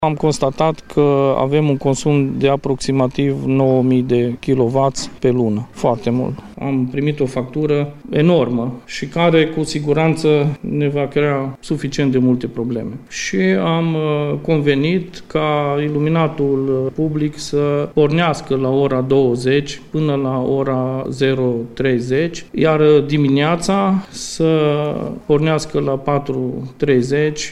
Primarul comunei Zăbrani, Dănuț Codrean, spune că autoritățile s-au arătat șocate de această factură.
03.-primar-zabrani.mp3